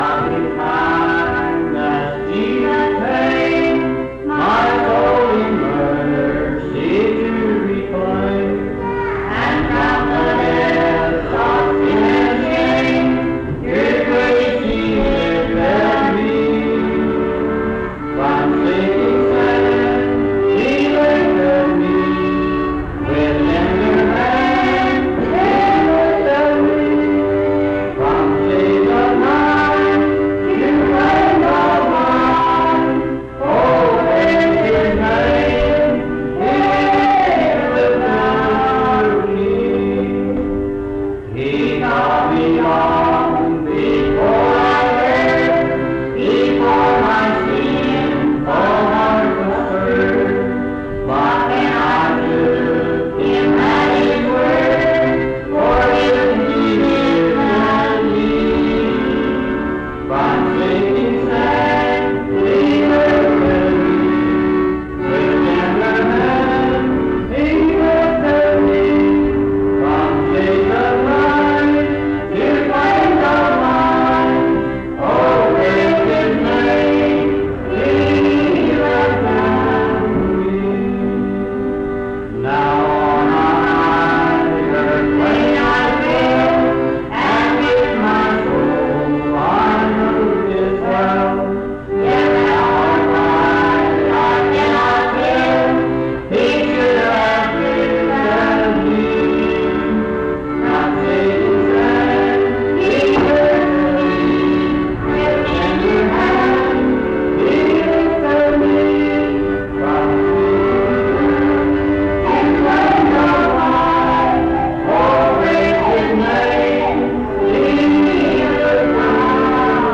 This recording is from the Monongalia Tri-District Sing.